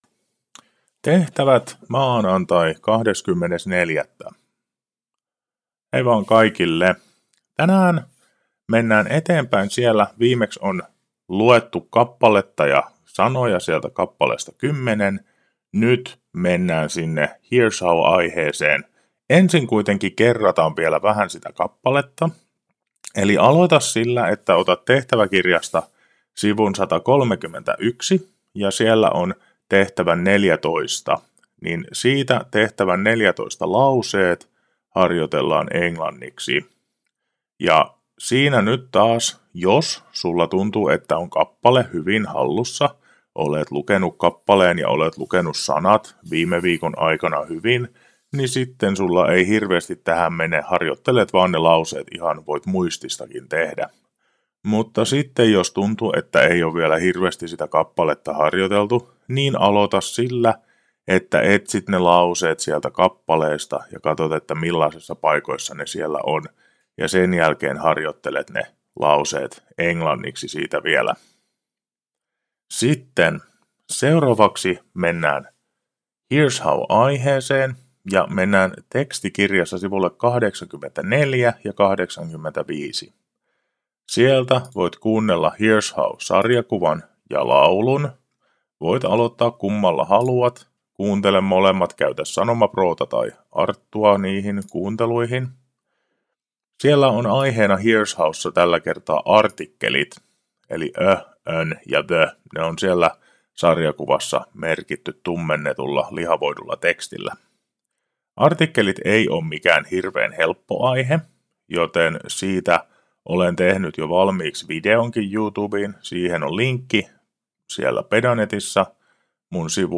Ohjeet puhuttuna: